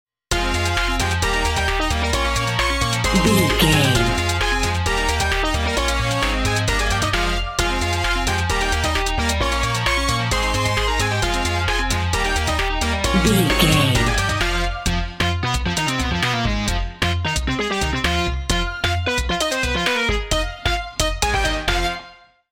Aeolian/Minor
Fast
bouncy
bright
cheerful/happy
funky
groovy
lively
playful
uplifting
synthesiser
drum machine